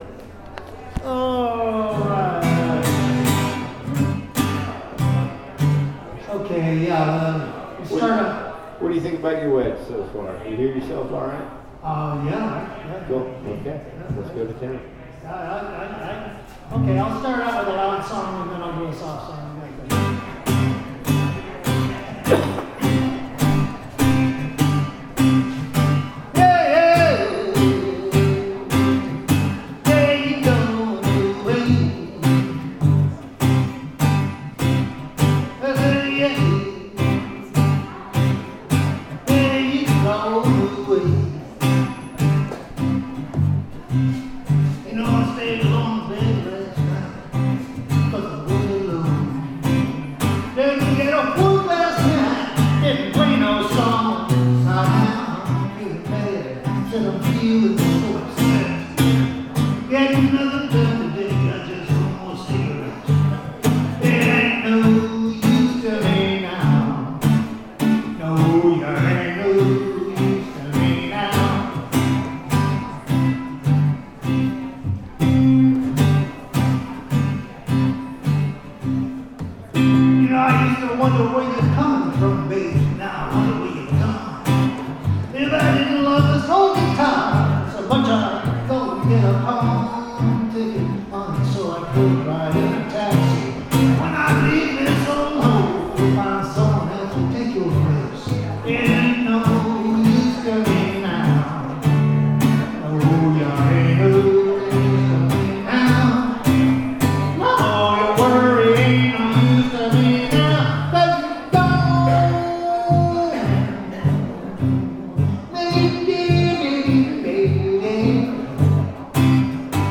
It was nice to play a solo show, it’s been a while.